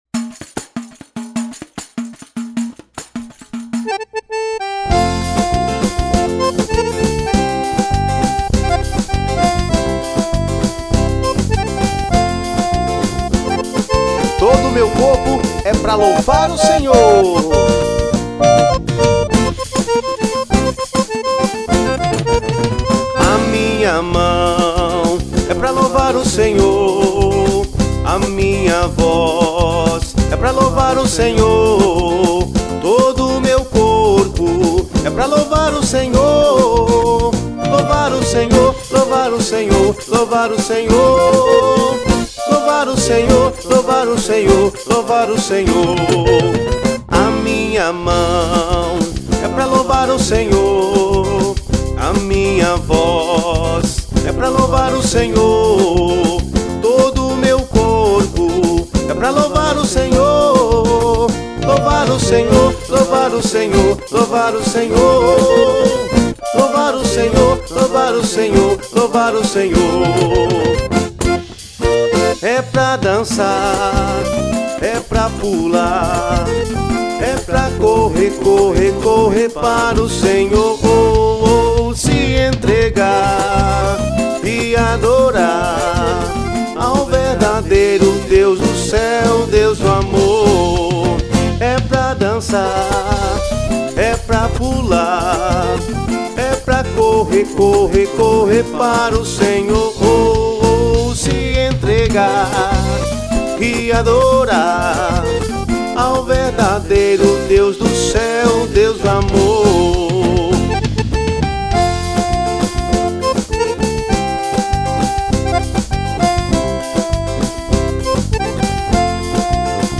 Gênero Católica.